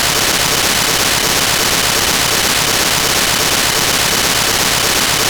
File:P25 Phase 2 TDMA CC.wav - Signal Identification Wiki
P25_Phase_2_TDMA_CC.wav